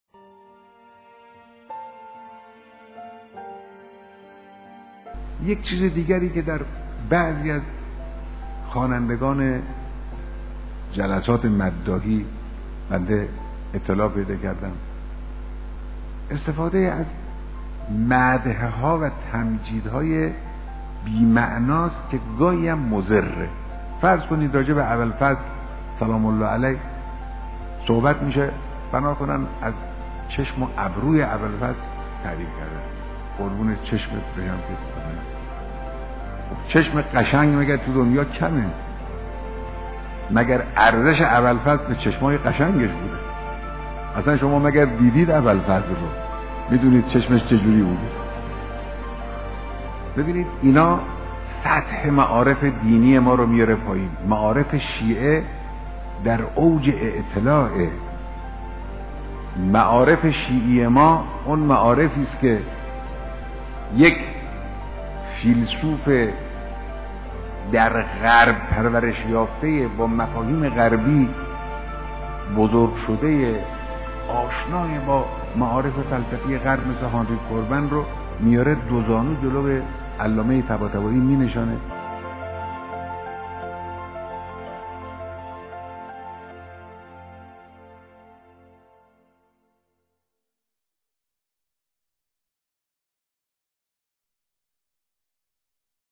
بیانات رهبری
دیدار مداحان اهل بیت